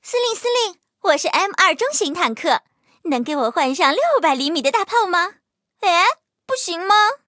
M2中坦登场语音.OGG